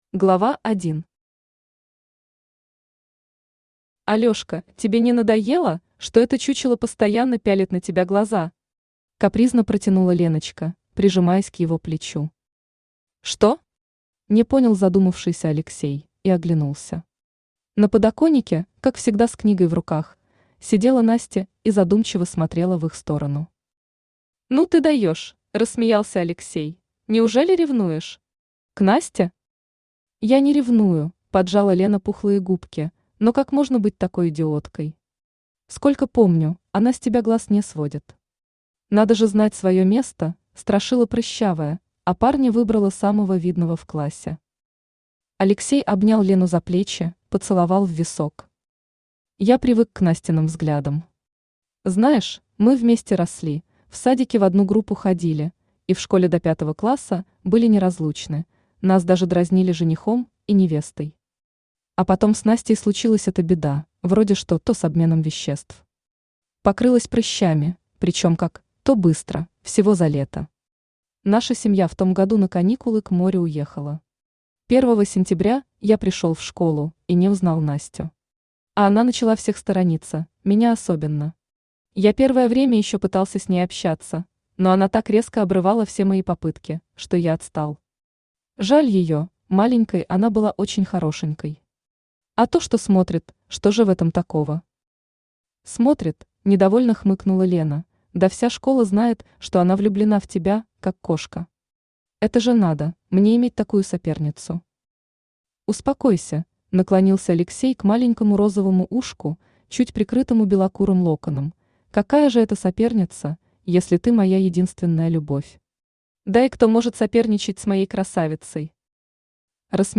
Аудиокнига Гадкий утёнок | Библиотека аудиокниг
Aудиокнига Гадкий утёнок Автор Нина Захарина Читает аудиокнигу Авточтец ЛитРес.